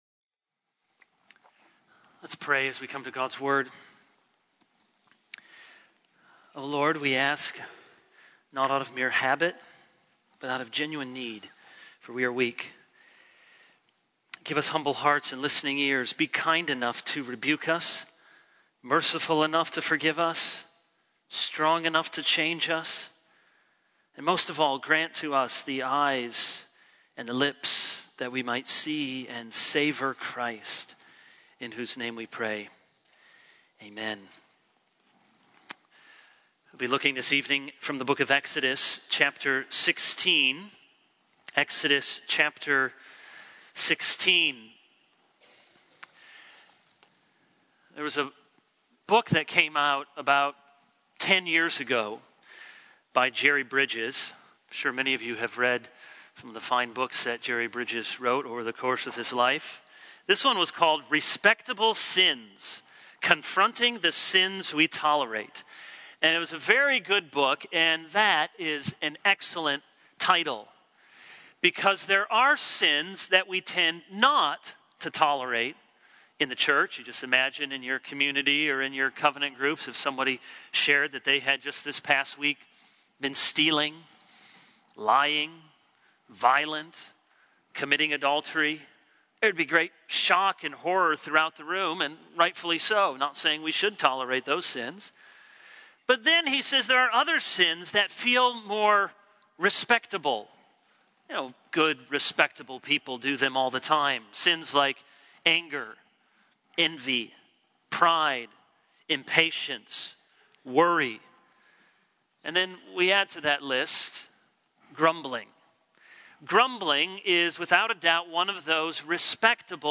This is a sermon on Exodus 16:1-36.